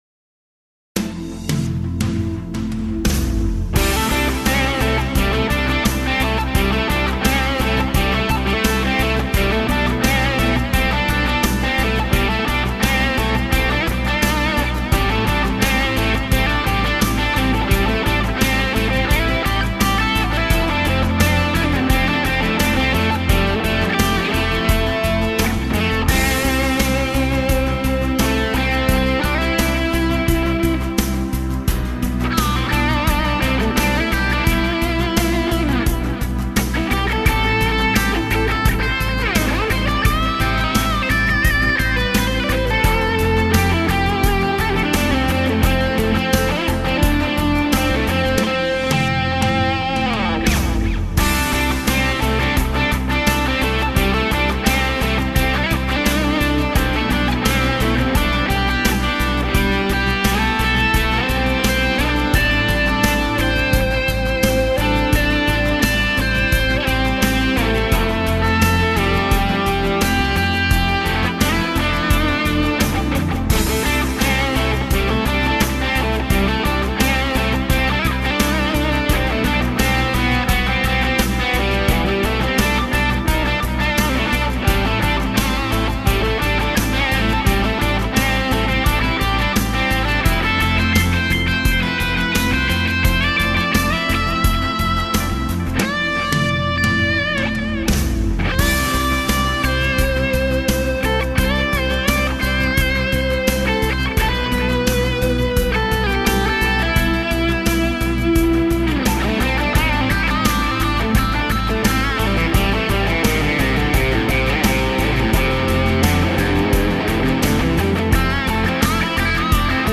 ������������ FERNANDES VRG + DigiTech PR355